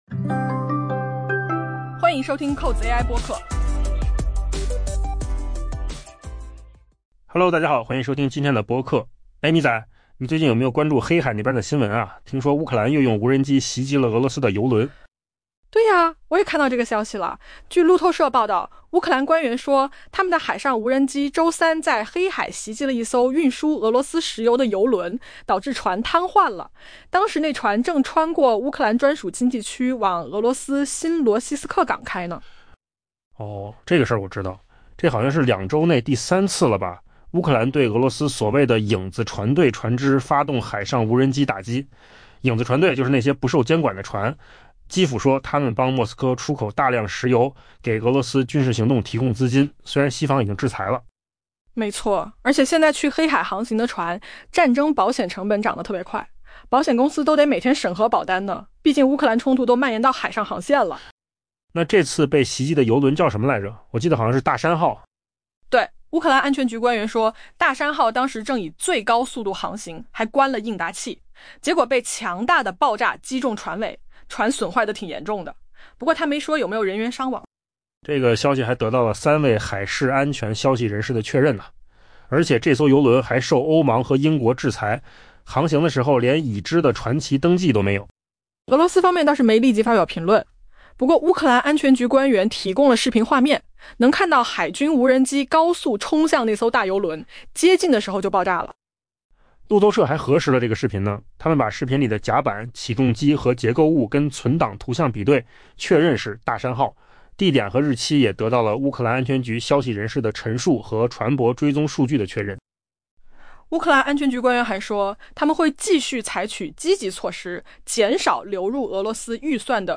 【文章来源：金十数据】AI播客：换个方
AI 播客：换个方式听新闻 下载 mp3 音频由扣子空间生成 据路透社报道，乌克兰官员称，乌克兰海上无人机于周三在黑海袭击并导致一艘运输俄罗斯石油的油轮瘫痪，当时该船正穿越乌克兰专属经济区驶往俄罗斯的新罗西斯克港。